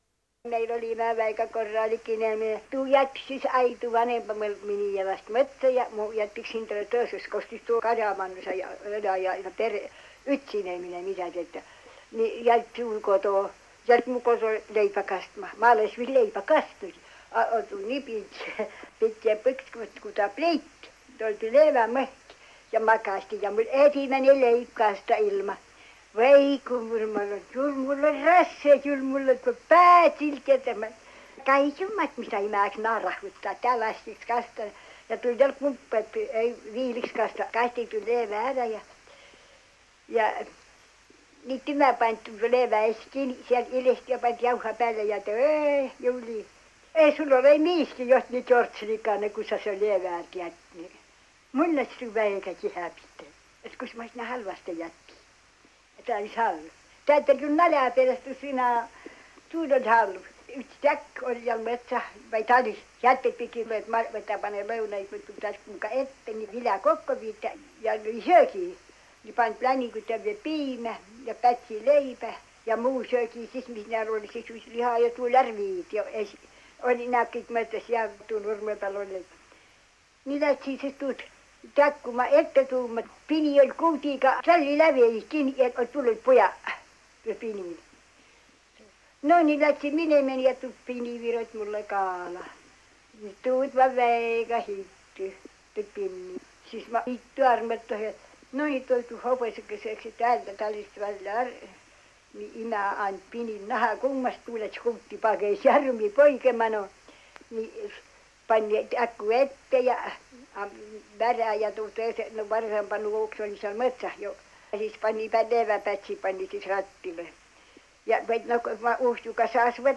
MurdekiikerVõru murreVVastseliina